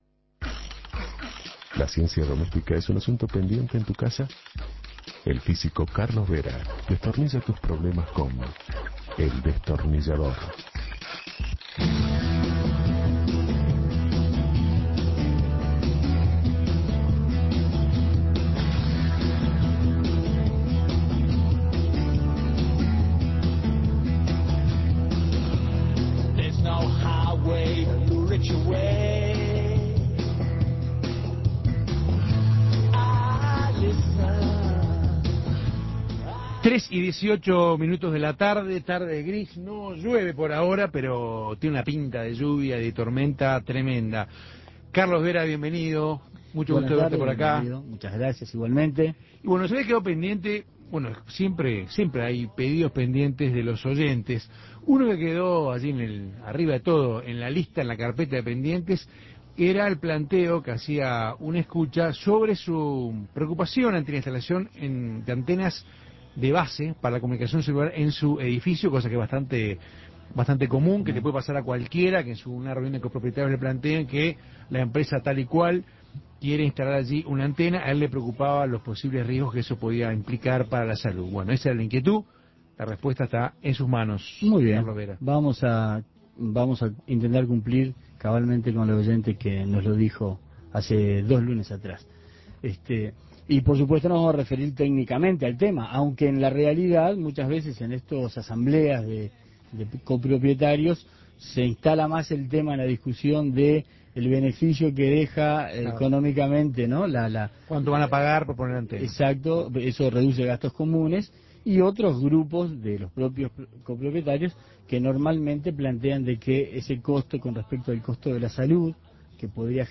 contesta consultas de los oyentes